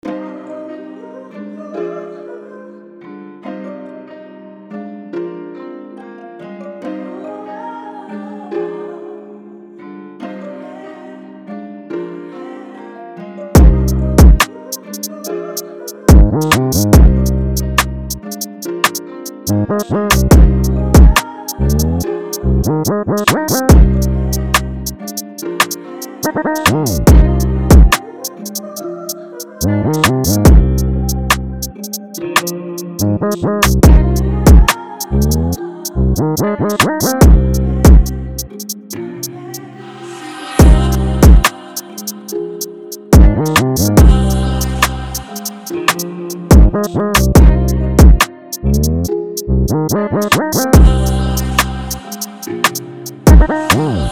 令人忍不住点头的律动